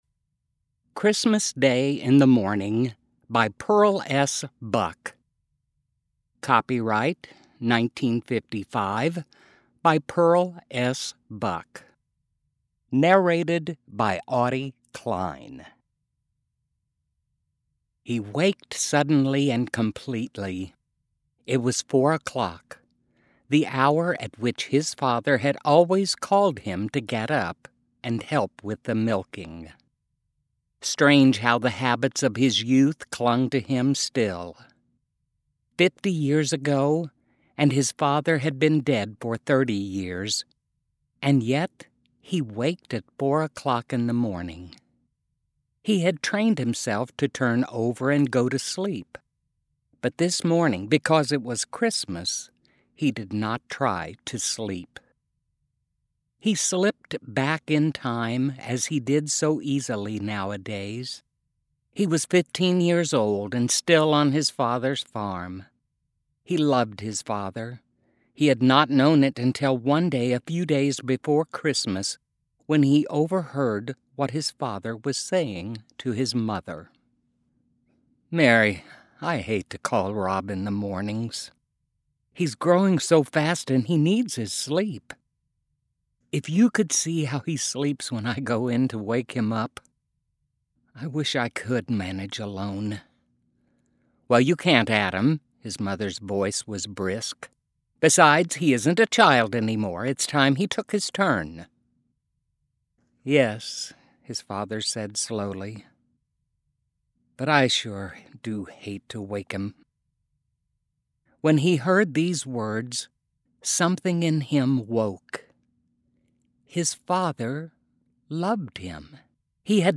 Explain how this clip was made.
Enjoy Holiday readings from the Wolfner Library recording booth and the wonderful volunteers who help make it all happen.